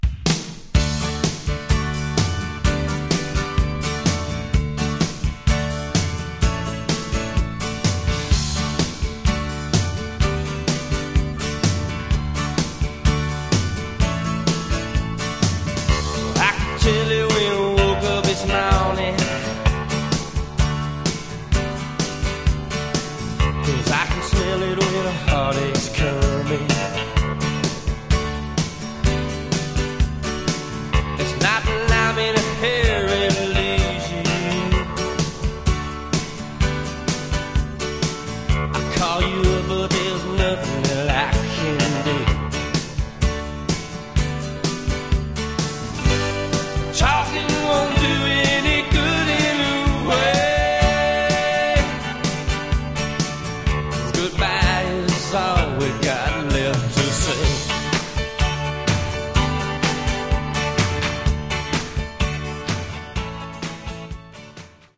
Genre: Rockabilly/Retro